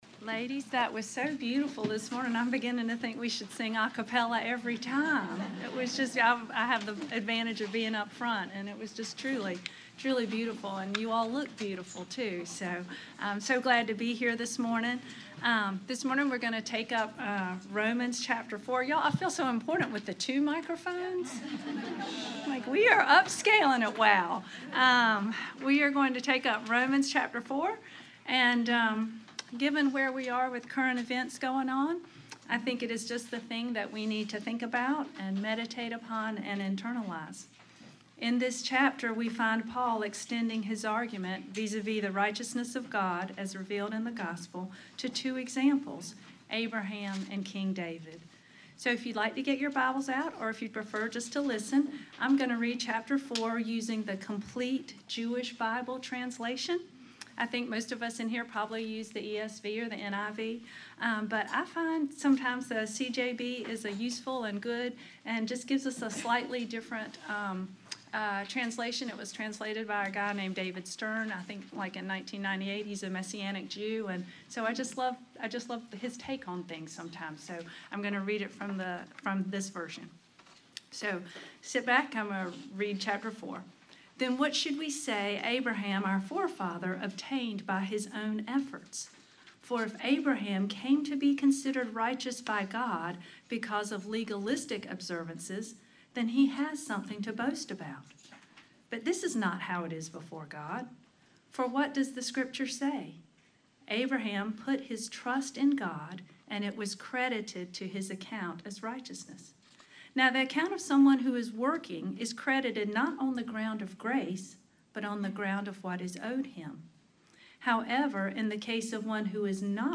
WOW-2024-Lecture-5.mp3